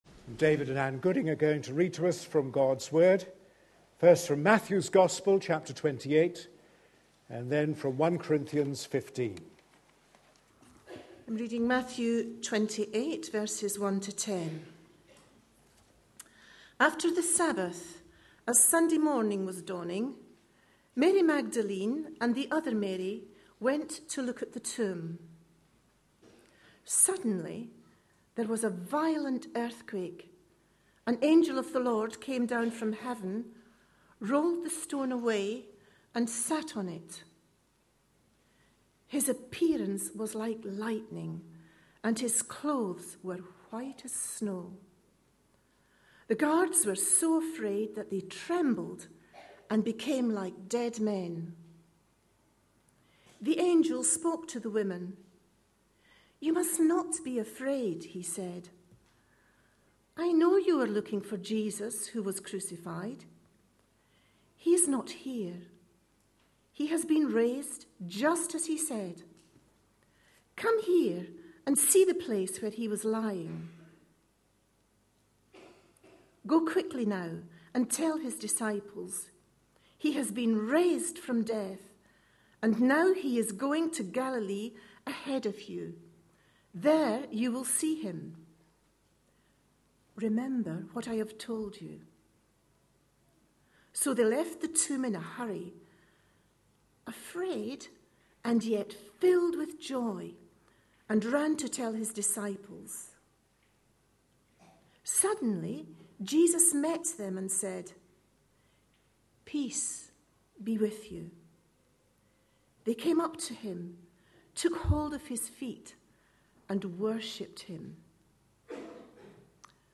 A sermon preached on 31st March, 2013, as part of our Passion Profiles and Places -- Lent 2013. series.